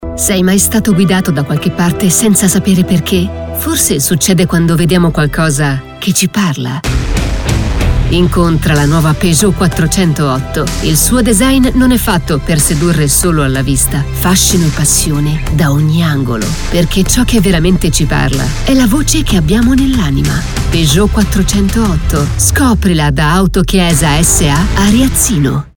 Voice Talent, Speaker, Script Translator and Adaptor, Actress, Teacher
Sprechprobe: eLearning (Muttersprache):
I have a soundproof home studio with professional sound absorber panels, Rode NT USB microphone and Vocal Booth Kaotica Eyeball